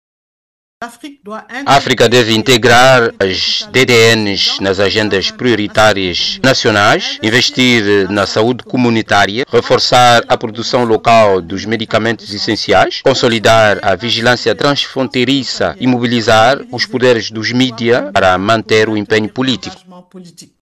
Ao intervir no evento, a ex-Ministra da Saúde do Senegal,  Awa Marie Coll Seck apontou as acções que considera  prioritárias no combate as doenças tropicais negligenciadas em África.